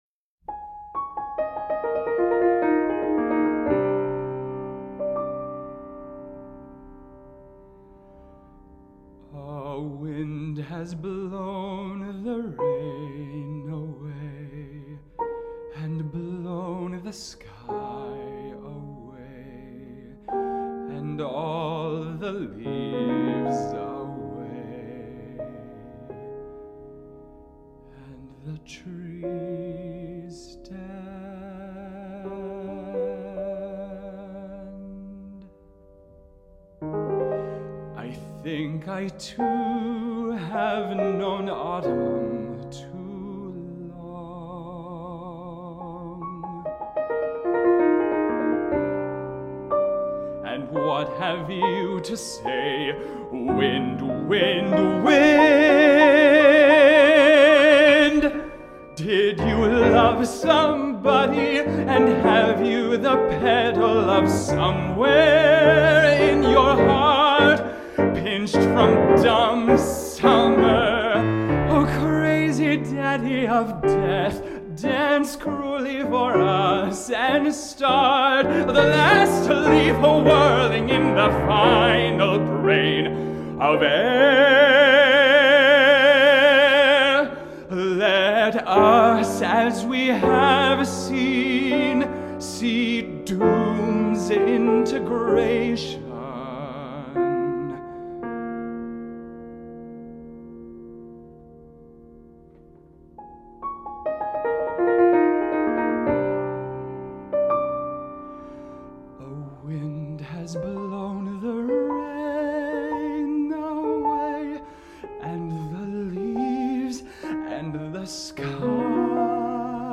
Individual Art Songs